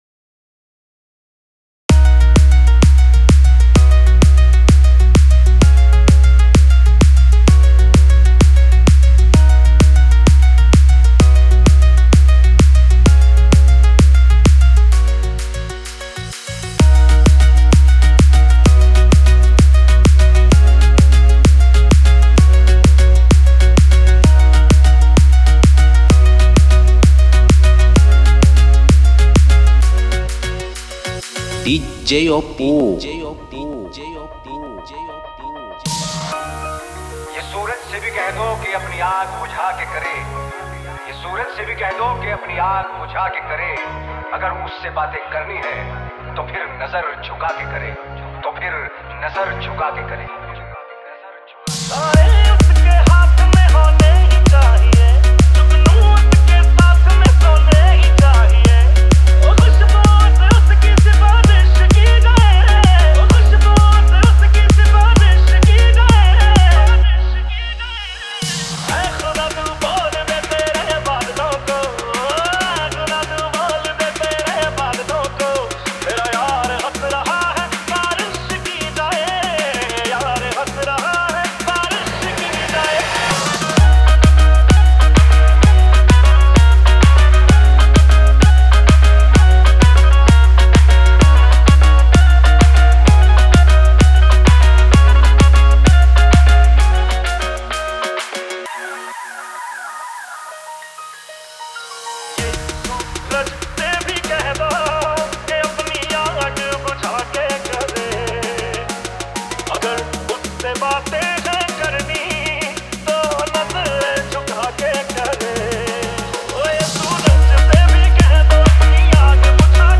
Edm Dance Love Story Remix